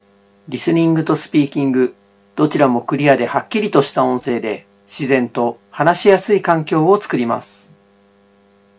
マイク音質もクリアで優秀
treVolo Uには全指向性好感度マイクが搭載されているので、双方向のライブ学習にも当然使えます。
これはスマホのレコーダーアプリで録音した音声なのですが、あまりの違いにビックリしますよね。